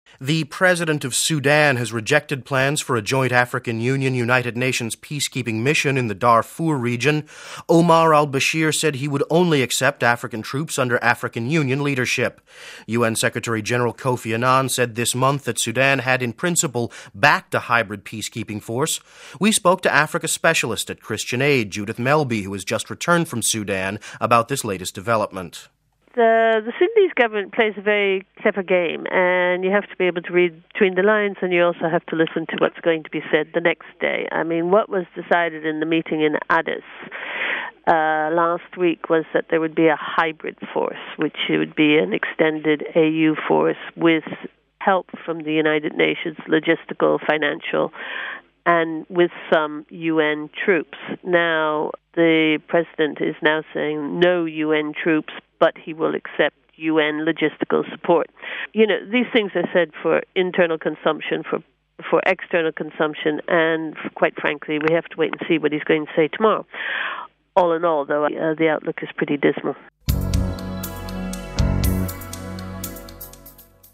Home Archivio 2006-11-28 18:44:40 Sudan Rejects UN Deal (28 Nov 06 - RV) Sudan has rejected a deal to put UN peacekeepers in the Darfur region. We spoke to a Christian humanitarian worker who has just returned from the country...